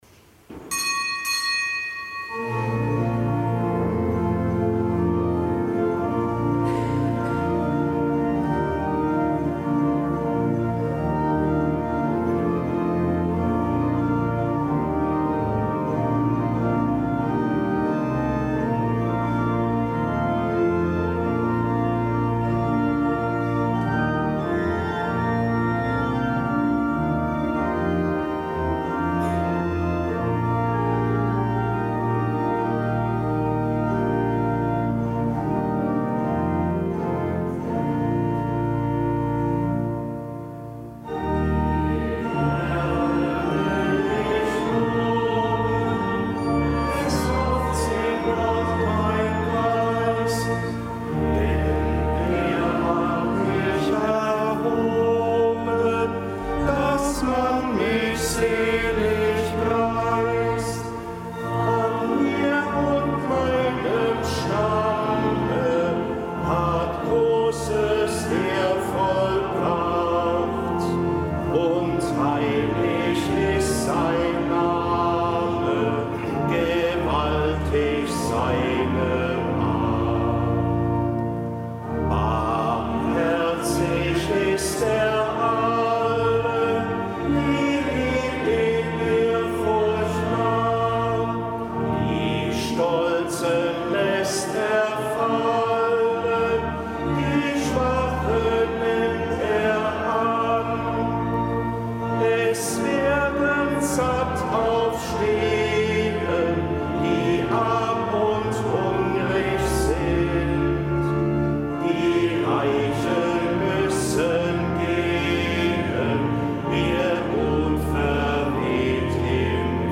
Kapitelsmesse am Samstag der vierten Osterwoche